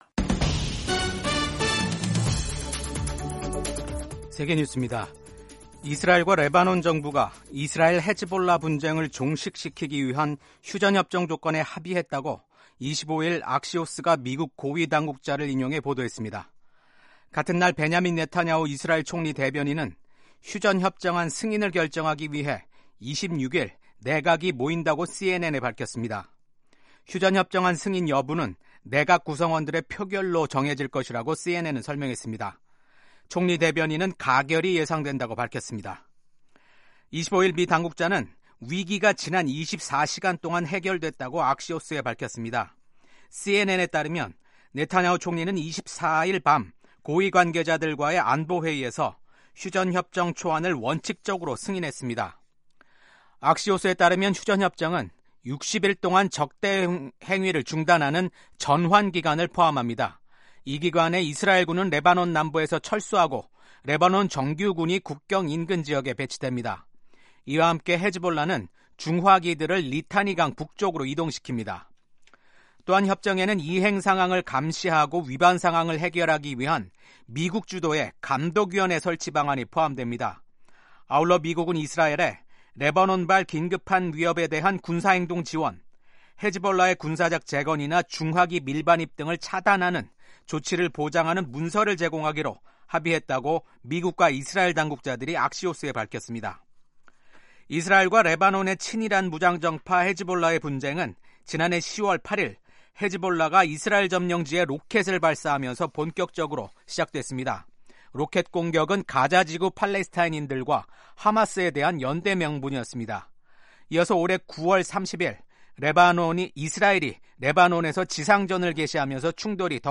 세계 뉴스와 함께 미국의 모든 것을 소개하는 '생방송 여기는 워싱턴입니다', 2024년 11월 26일 아침 방송입니다. 도널드 트럼프 미국 대통령 당선인이 최근 바이든 정부의 대인지뢰 사용 승인 등 결정으로 우크라이나 전쟁이 확전되는 것을 크게 우려하고 있는 것으로 알려졌습니다. 29차 유엔기후변화협약 당사국총회(COP29)가 회의 일정을 연장해 가며 기후위기 대응 취약국가를 돕기 위한 선진국 분담금 규모를 늘리기로 합의했습니다.